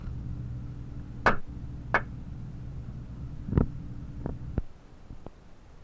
I recorded two hand claps on my cassette deck, one at the beginning, one at the end. I dumped that to DAW, then I copied that from the DAW to the cassette and then I dumped it back into my DAW, lined up the first clap and then looked at the differential between tracks at the end.
View attachment 143273 View attachment 143277 The differential is approximately 0.7 seconds over a 6 minute recording.